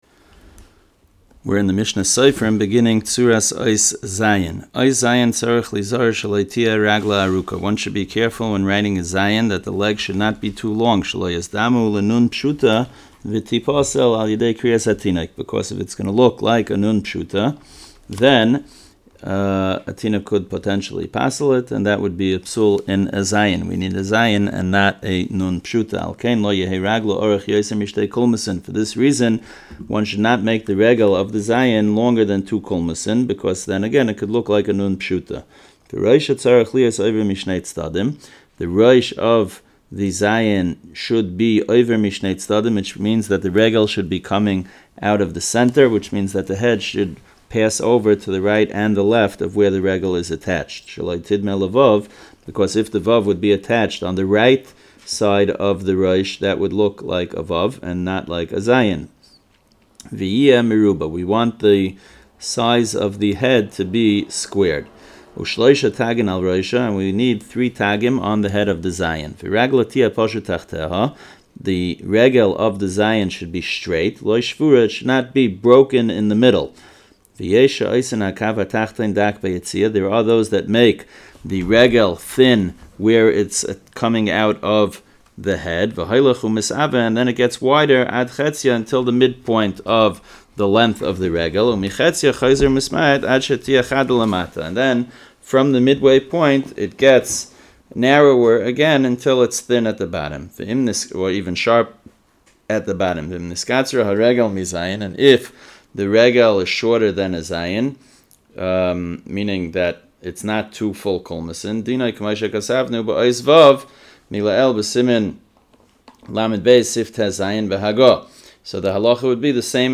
Audio Shiurim - The STa"M Project | Kosher-Certified Mezuzos, Tefillin & STa”M